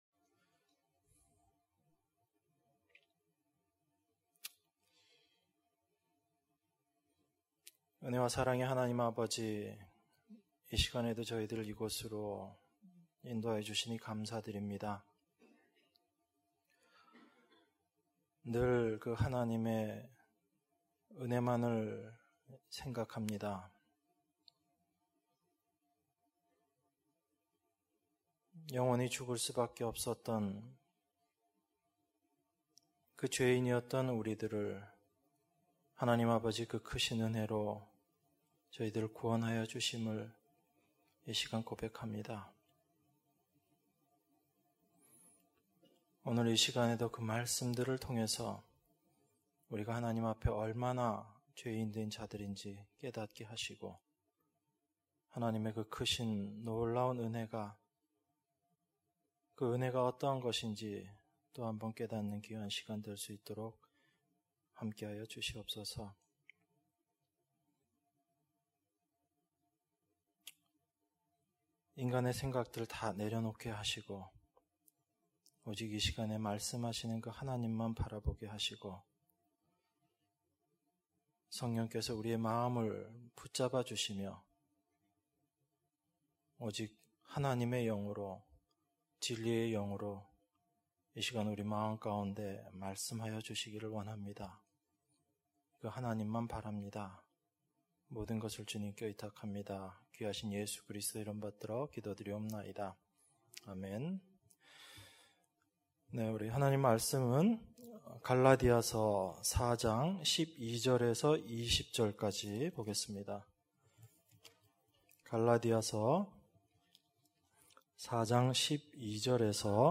수요예배 - 갈라디아서 4장 12절 - 20절